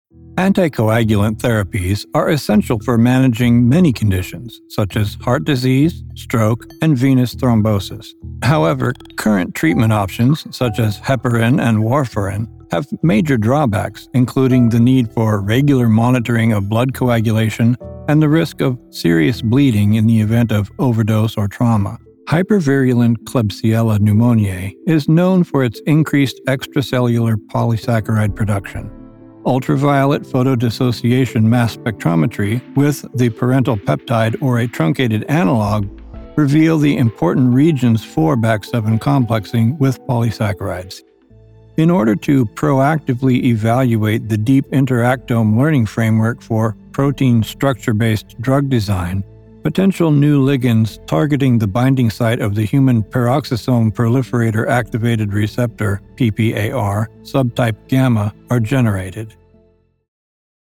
Medical Narration
Middle Aged
Senior